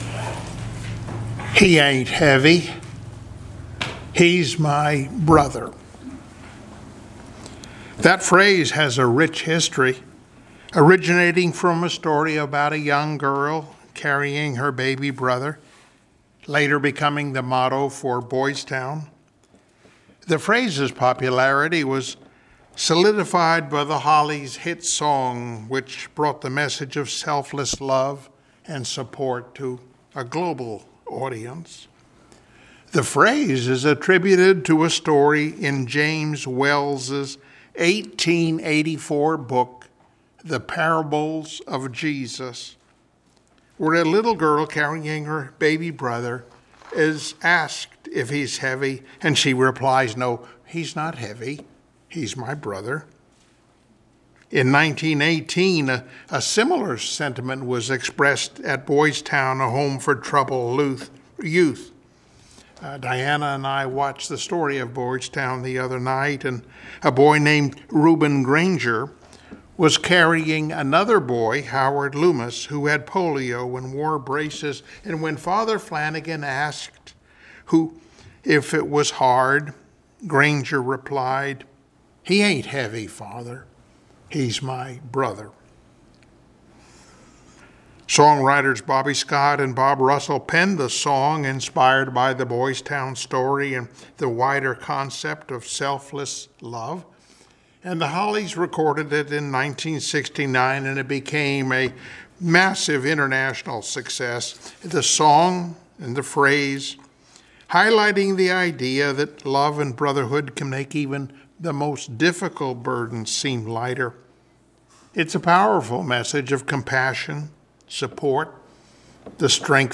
Service Type: Sunday Morning Worship Topics: Jesus Our Brother , Joesph and Jesus Compared , The Brothers reunited